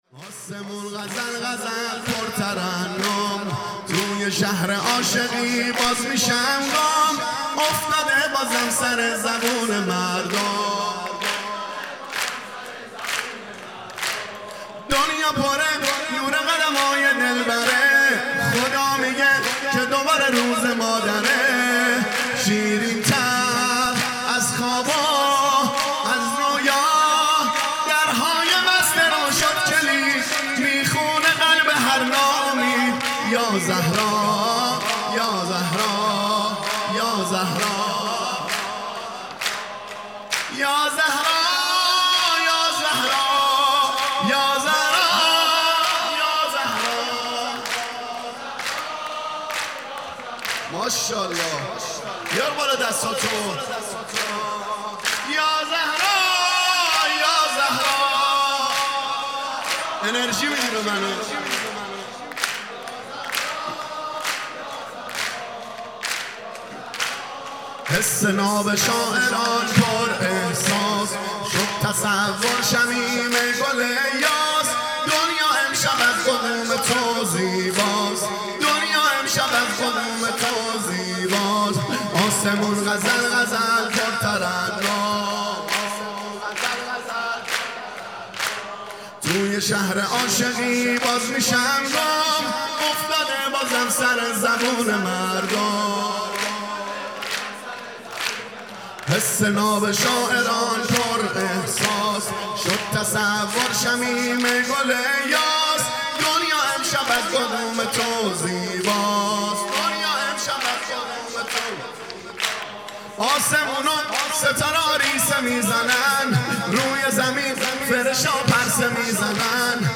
سرود
آسمون غزل غزل پر ترنم|ولادت حضرت زهرا (س) ۹۷
هیئت دانشجویی فاطمیون دانشگاه یزد